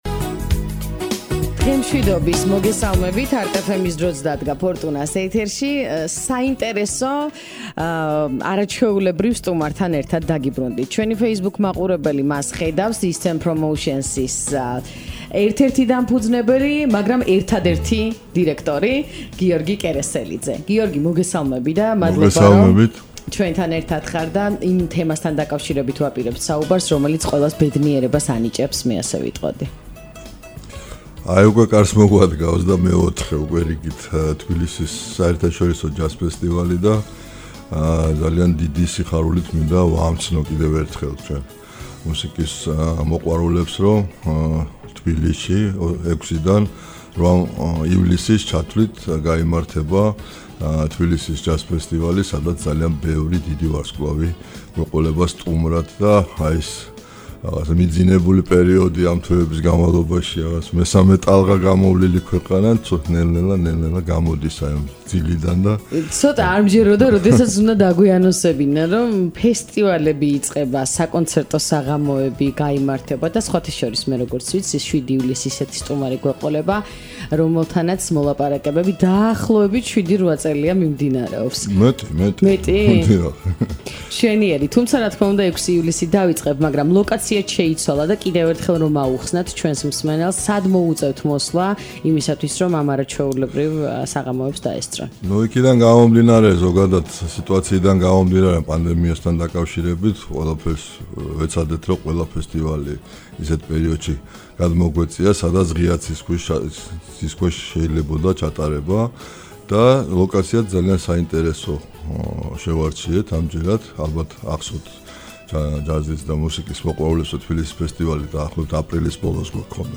გადაცემის ლაივი LIVE